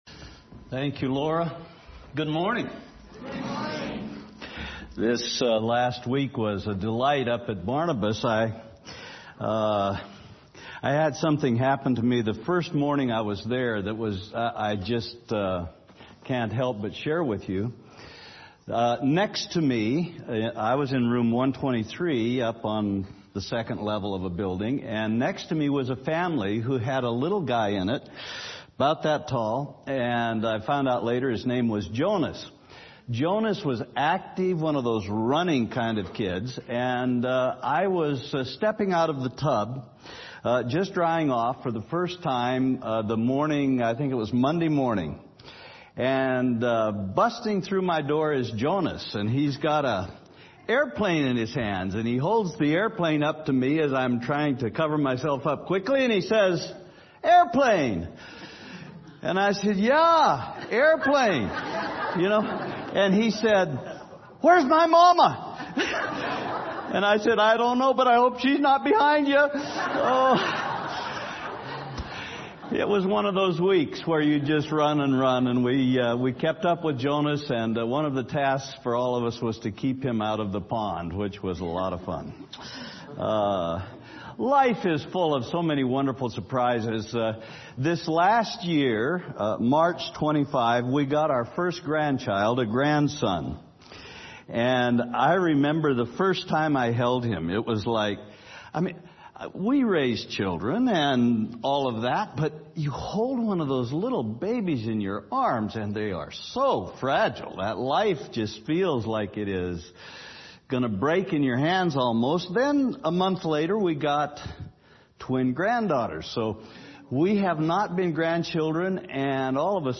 Sermons | Olivet Baptist Church